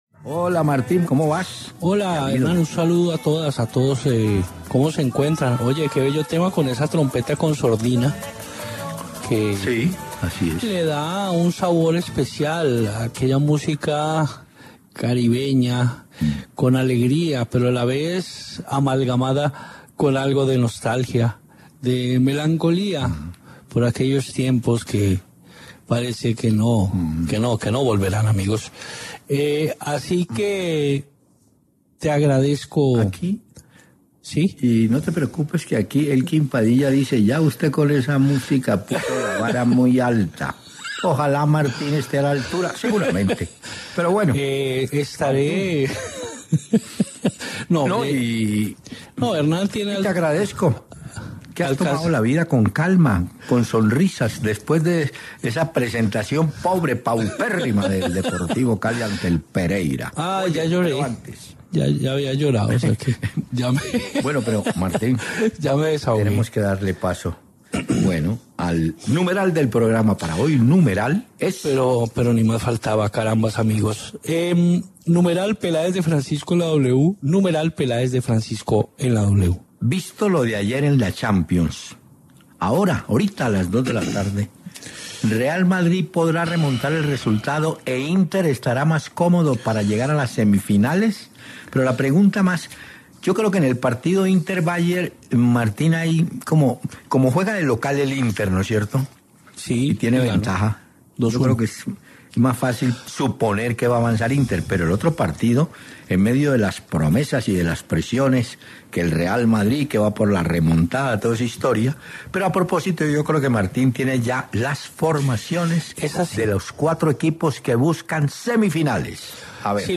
Una hora para charlar de lo que más nos gusta: el fútbol, el de ayer y el de hoy, con grandes anécdotas. Todo, acompañado de buena música.